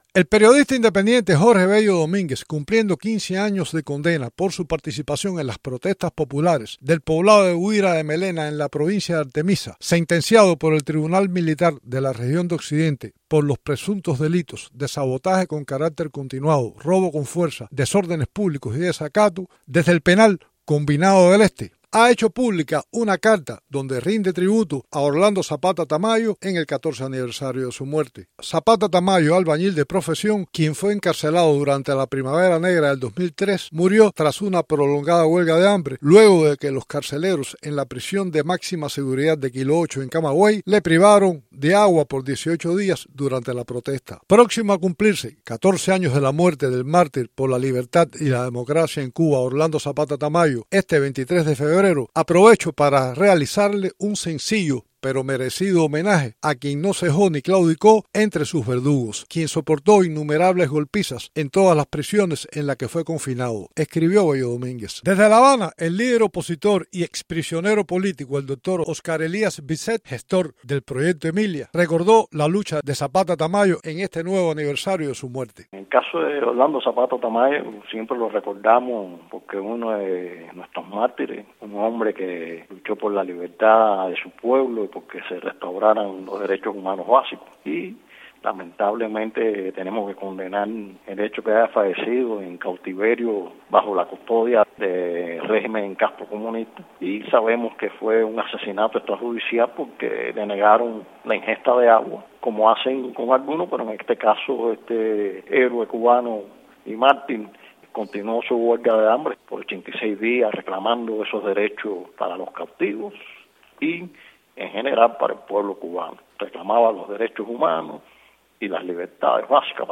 Un periodista encarcelado, asi como un iíder historico de la disidencian recuerdan a Orlando Zapata Tamayo.